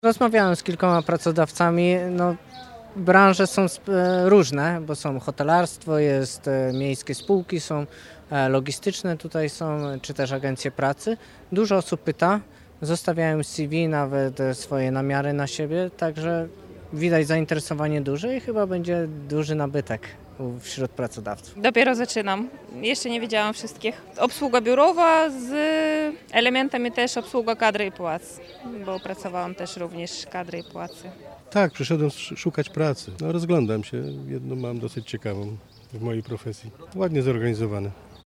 Zapytaliśmy jakie nastroje panują wśród uczestników.